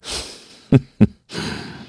Clause_ice-Vox-Laugh_kr.wav